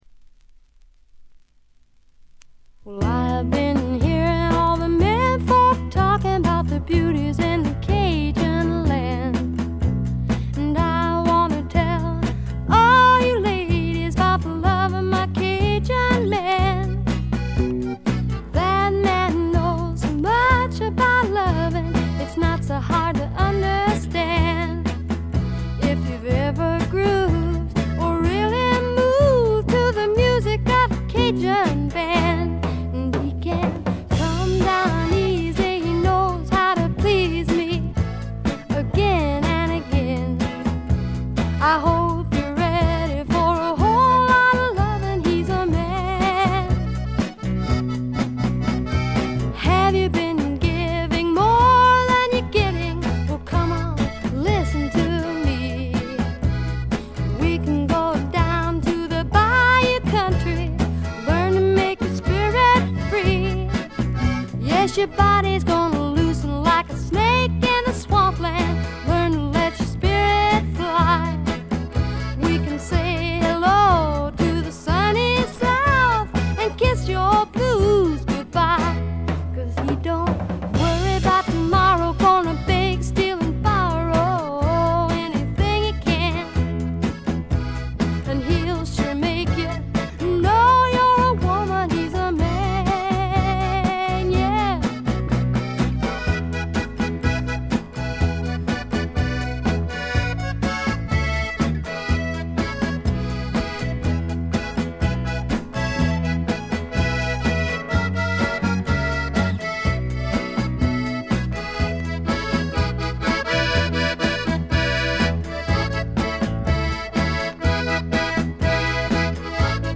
女性シンガーソングライター
バックがしっかり付いた重厚な音作りで、フォーキーな曲、アーシーな曲からハードな曲まで一気に聴かせます。
試聴曲は現品からの取り込み音源です。
Accordion